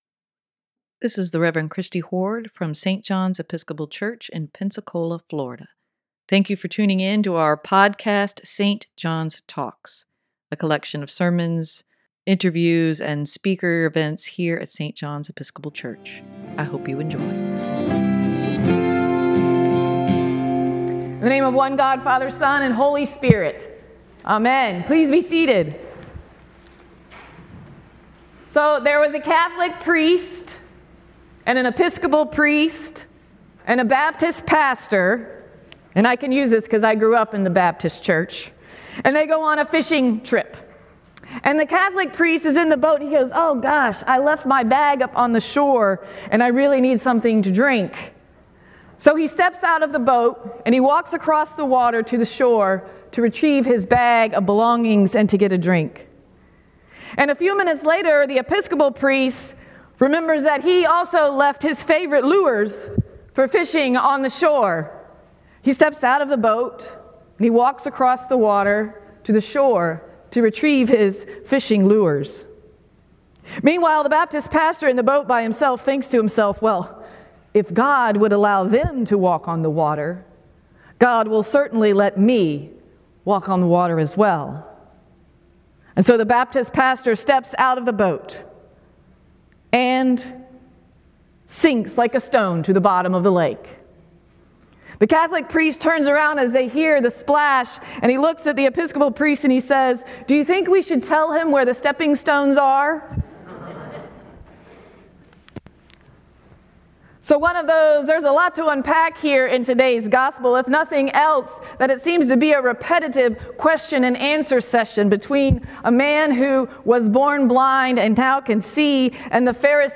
Sermon for March 19, 2023: Stepping Stones to the Future
sermon-3-19-23.mp3